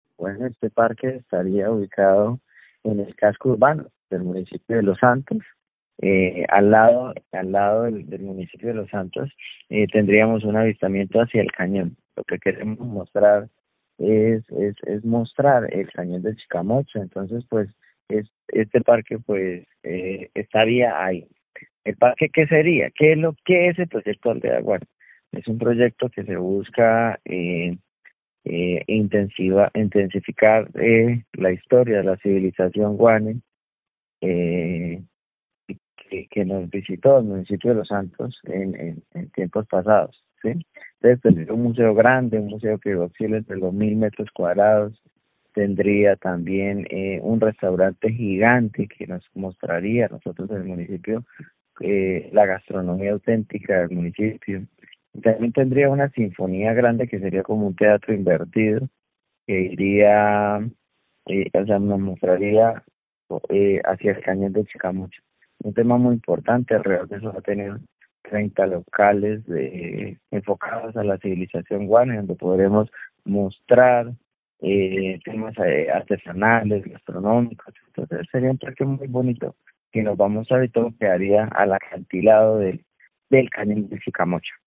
Diego Armando Mendoza, alcalde de Los Santos
El alcalde de Los Santos, Diego Armando Mendoza, aseguró en Caracol Radio que esta especie de “museo Guane” quedará ubicado en el casco urbano del municipio, para de alguna manera potenciar el turismo en esta región del departamento.